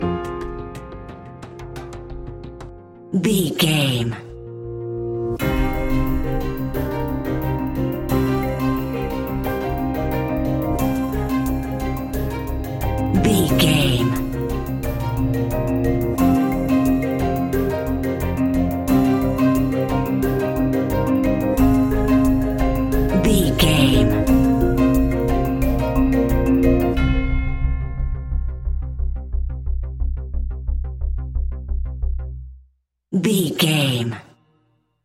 Ionian/Major
C♭
electronic
techno
trance
synths
synthwave
instrumentals